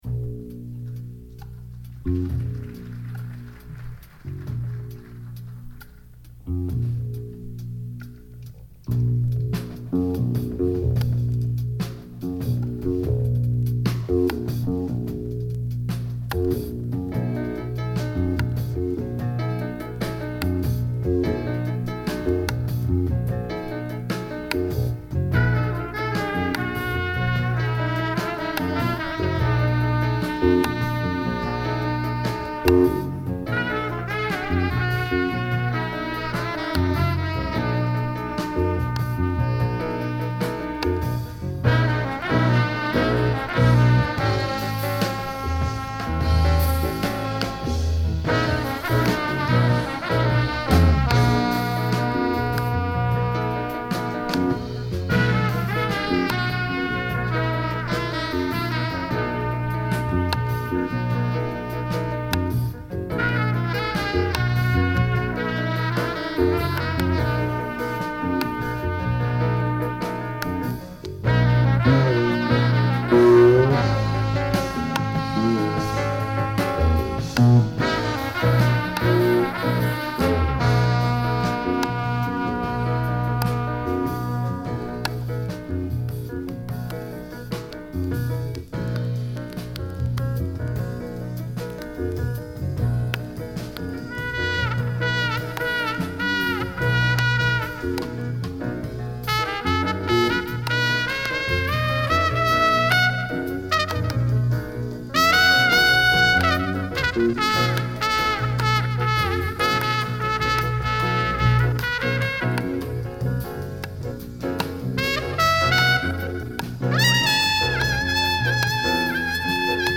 Recorded at a jazz festival on the Volga river